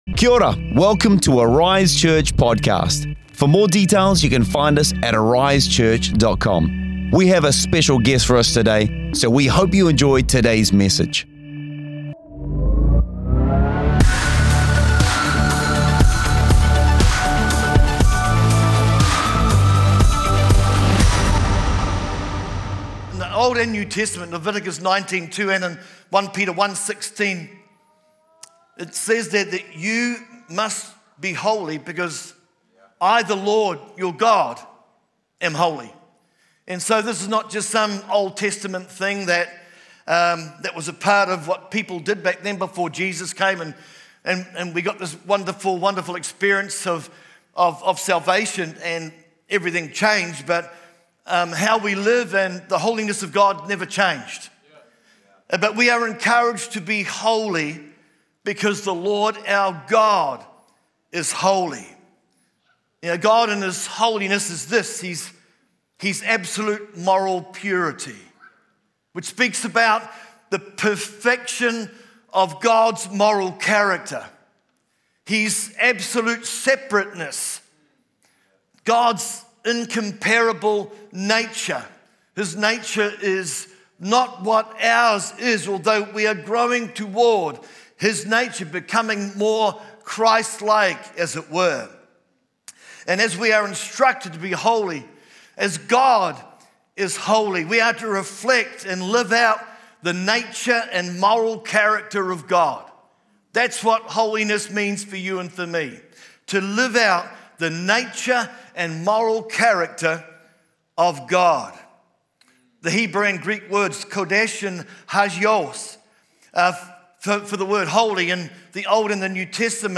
Impact Our World … continue reading 427 episodes # Religion # Church # Arise # New # Zealand # New Zealand Christianity # Christianity # Arise Church # Jesus # Sermons # Messages